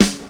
• Nineties Studio Pop-Folk Snare Sound G Key 706.wav
Royality free acoustic snare sample tuned to the G note. Loudest frequency: 1825Hz
nineties-studio-pop-folk-snare-sound-g-key-706-Fxp.wav